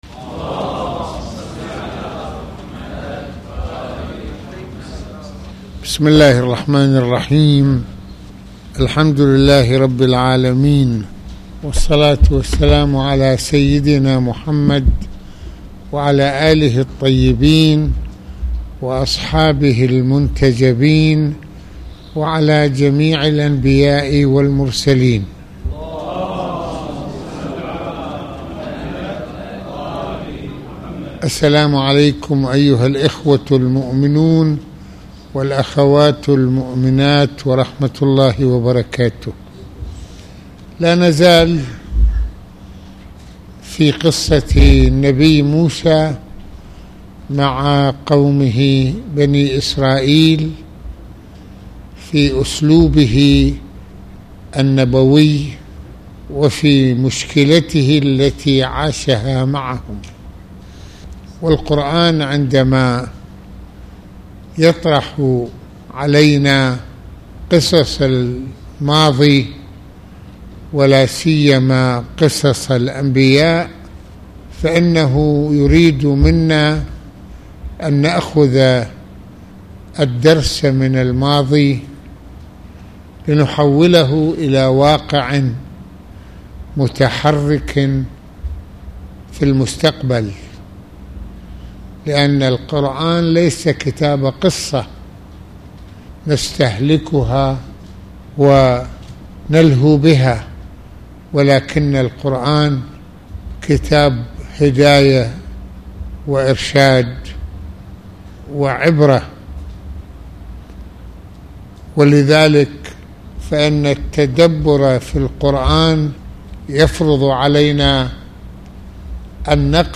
المناسبة : خطبة الجمعة المكان : مسجد الإمامين الحسنين (ع)